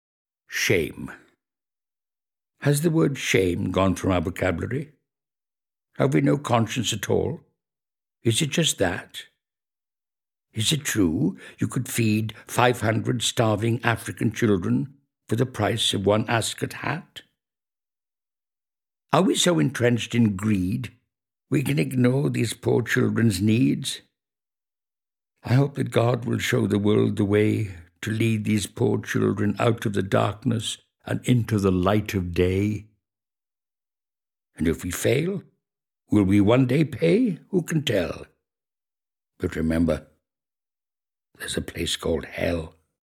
Click here to play poem read by Victor Spinetti